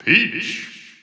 The announcer saying Peach's name in English and Japanese releases of Super Smash Bros. 4 and Super Smash Bros. Ultimate.
Peach_English_Announcer_SSB4-SSBU.wav